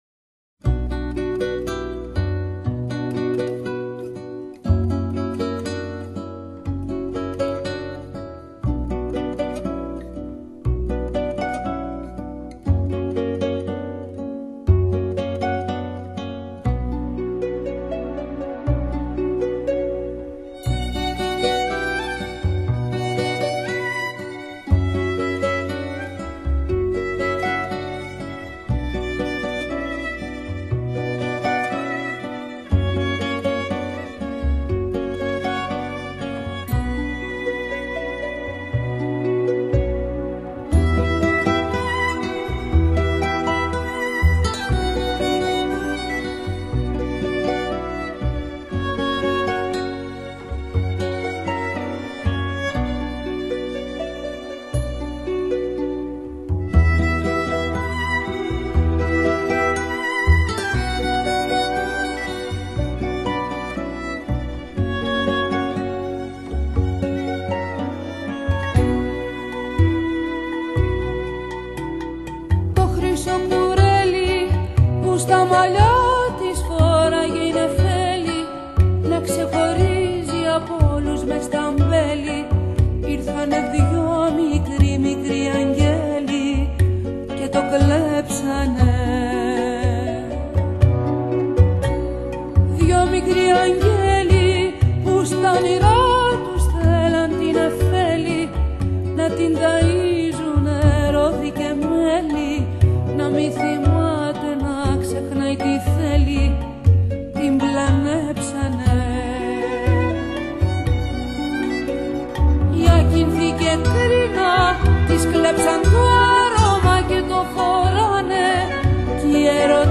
Tango(탱고)